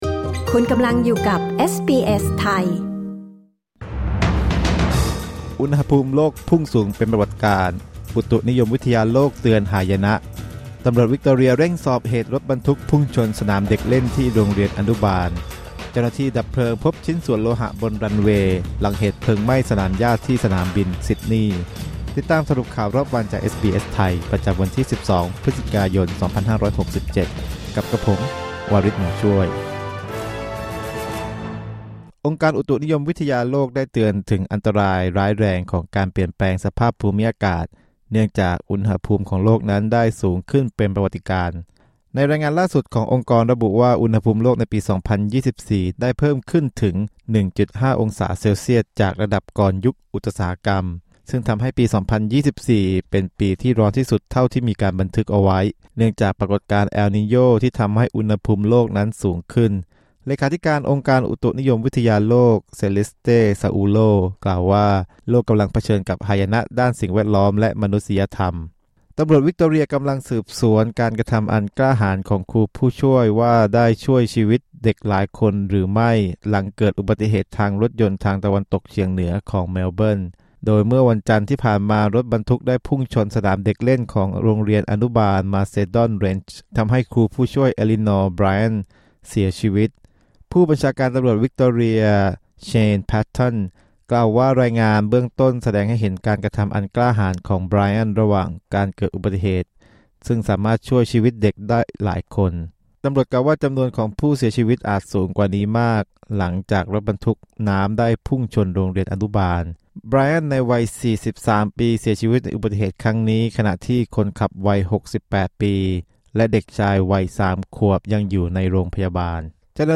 สรุปข่าวรอบวัน 12 พฤศจิกายน 2567